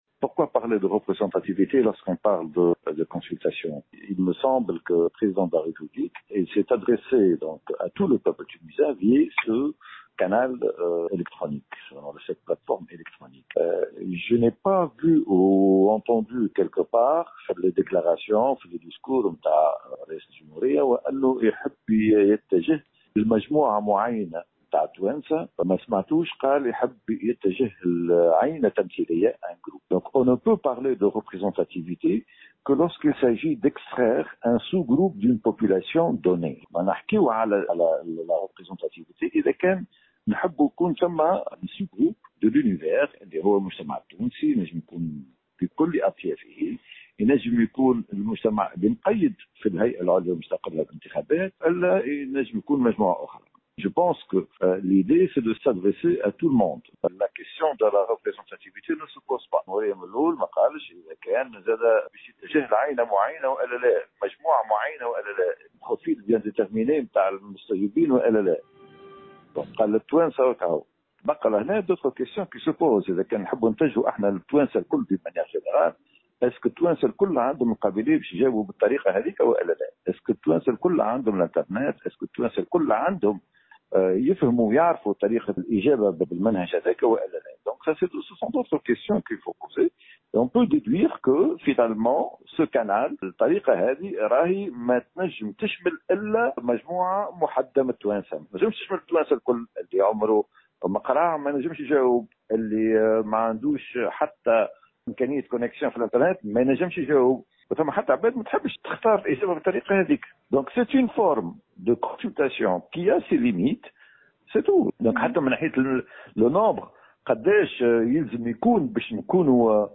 Dans une déclaration accordée aujourd’hui à Tunisie Numérique